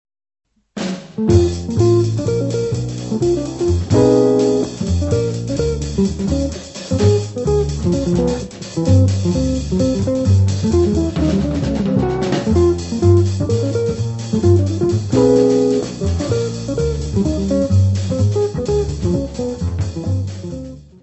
guitarra
saxofone alto e saxofone soprano
trombone
bateria
contrabaixo.
Área:  Jazz / Blues